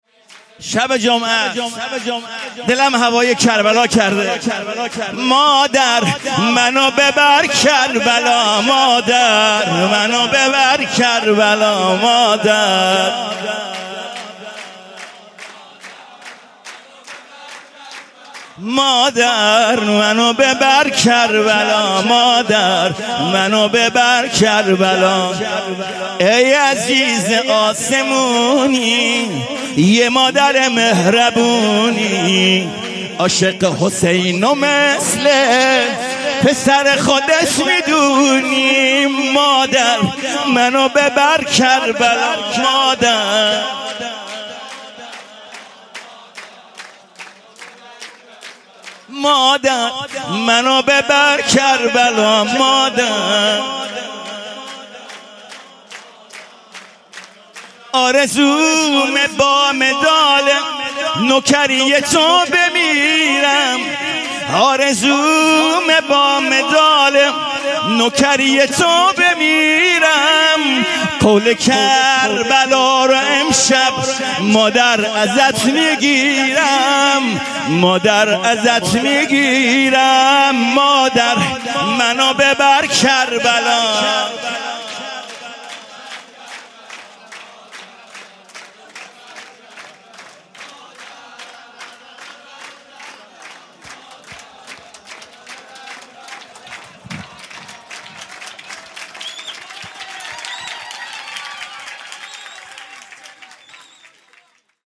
مولودی | مادر منو ببر کربلا
شب اول جشن میلاد حضرت زهرا سلام الله علیها